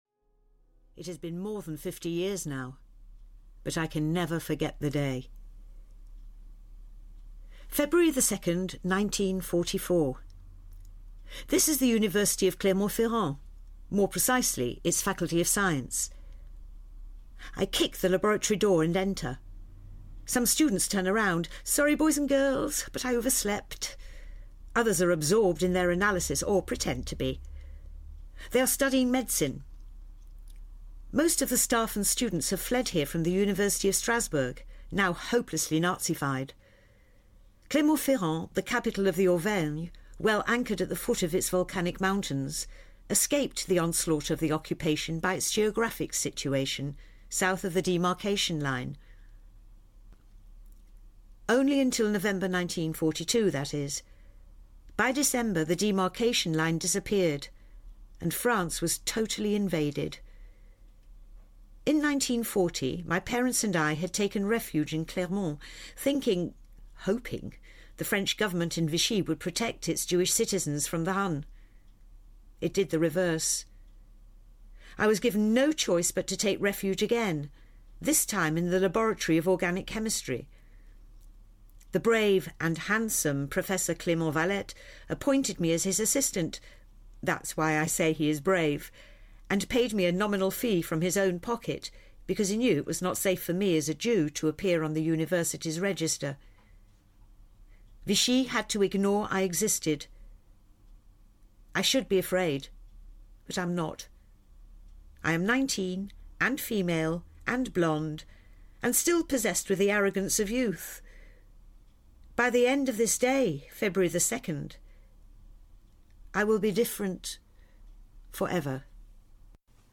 A Packhorse Called Rachel (EN) audiokniha
Ukázka z knihy